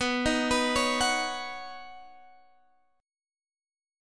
Badd9 Chord (page 3)
Listen to Badd9 strummed